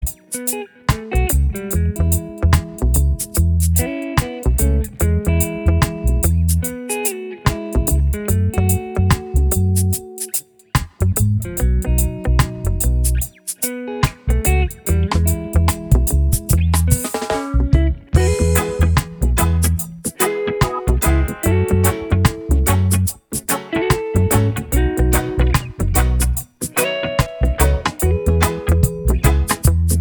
Genre: Voiceless